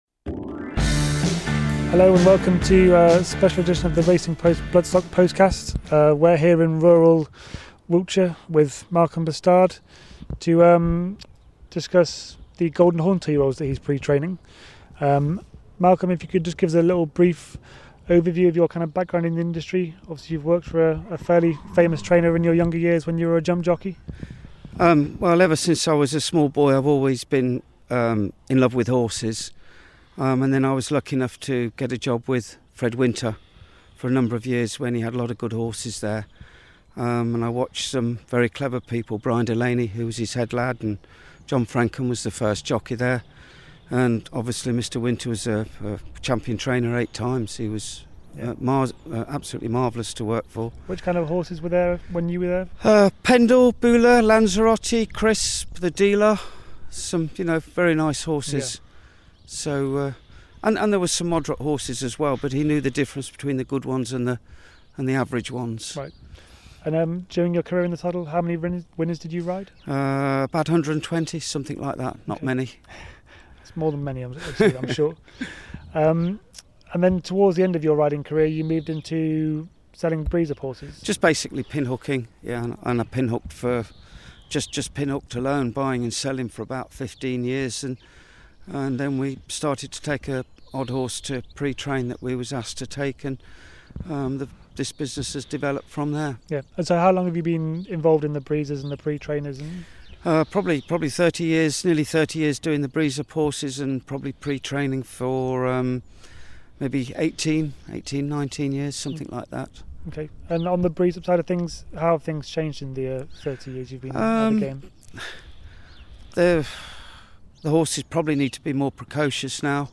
On location